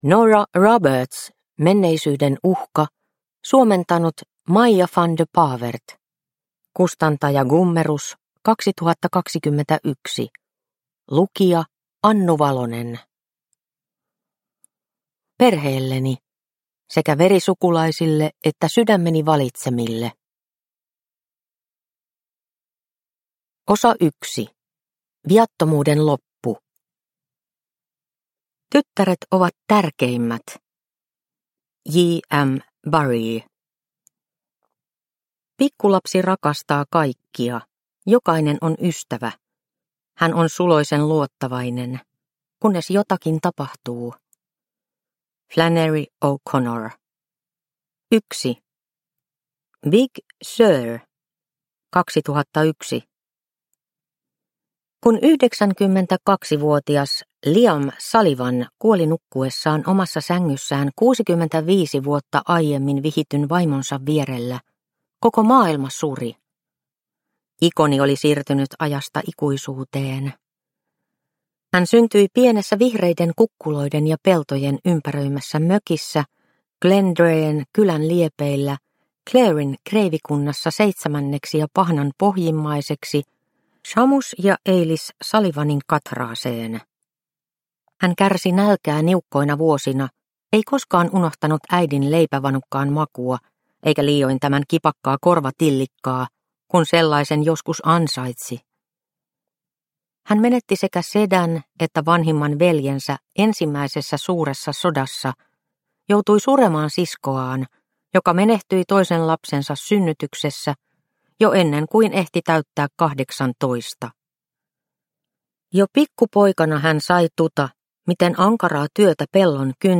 Menneisyyden uhka – Ljudbok – Laddas ner